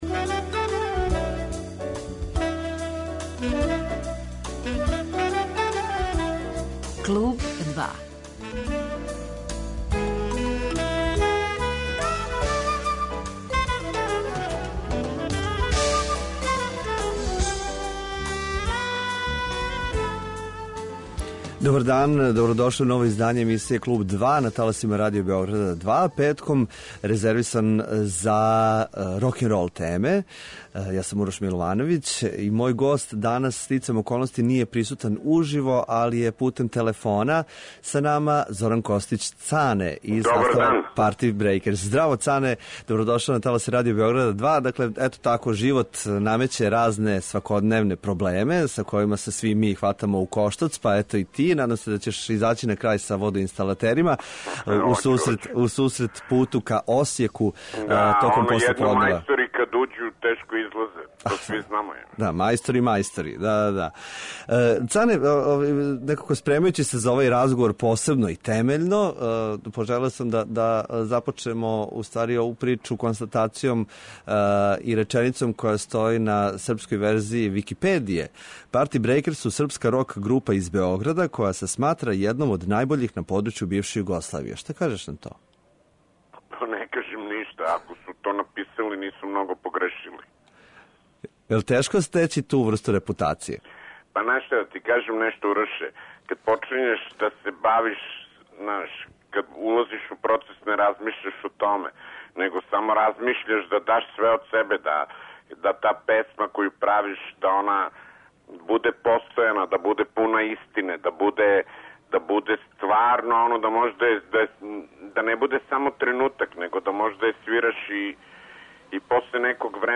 Гост емисије биће Зоран Костић Цане.